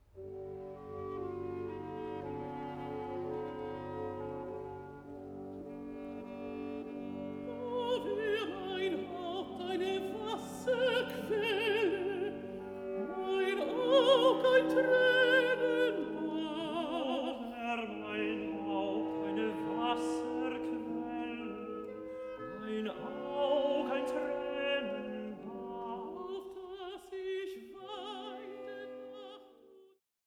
Duett (Hanna, Achicam)